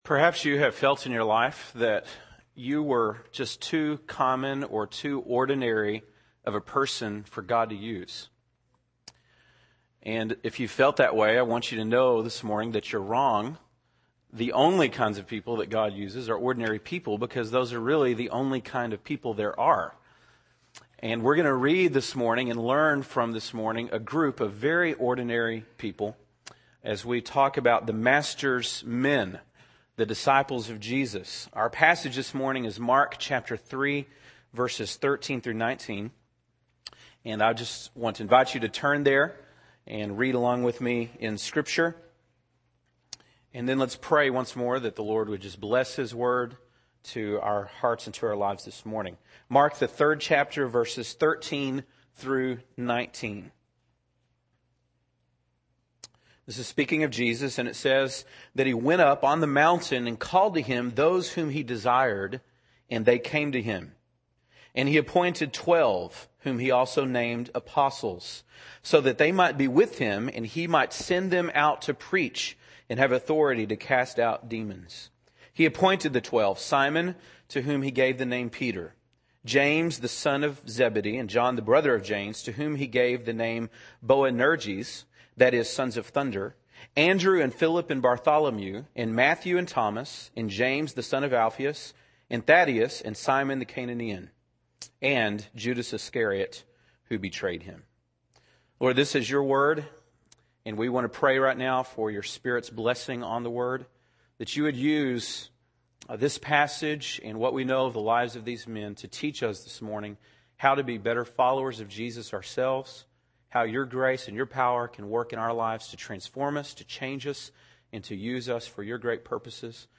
( Sunday Morning )